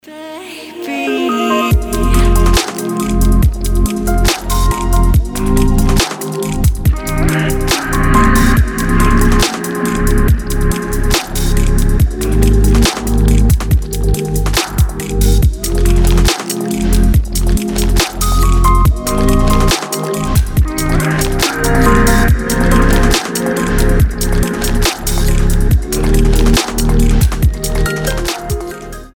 • Качество: 320, Stereo
женский вокал
мелодичные
спокойные
Electronica
chillout
чувственные
расслабляющие
Стиль: liquid drum&bass, future garage